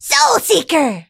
willow_ulti_vo_02.ogg